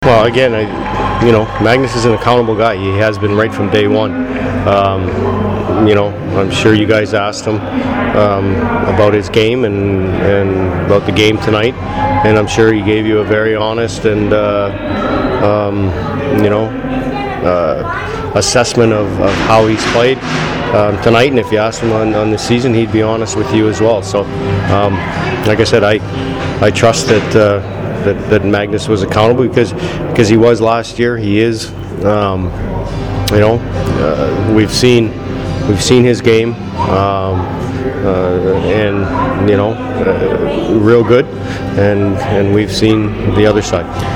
post-game interviews